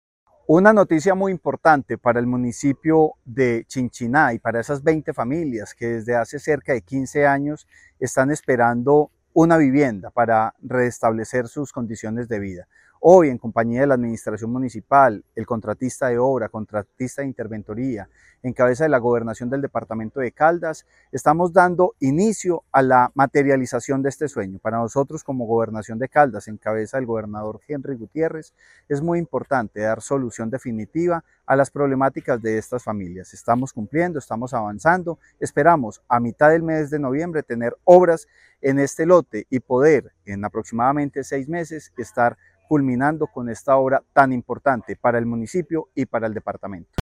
Francisco Javier Vélez Quiroga, secretario de Vivienda y Territorio.